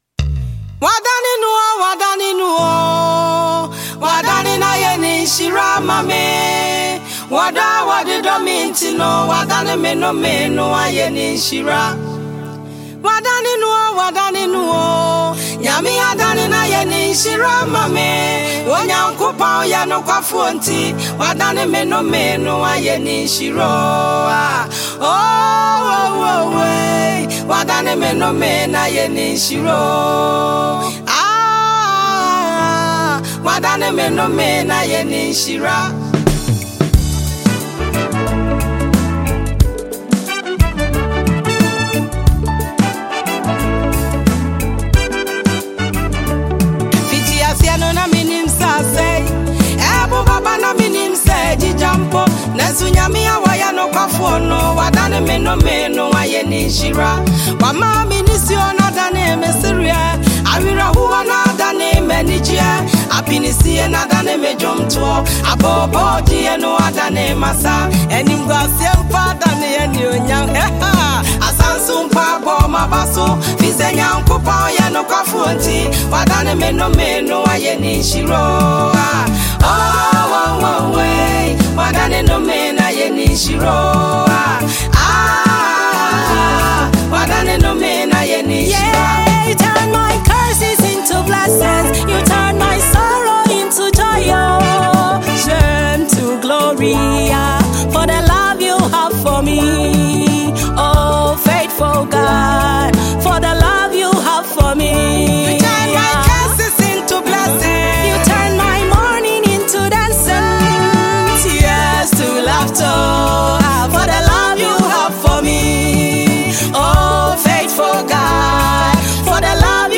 Ghanaian gospel musician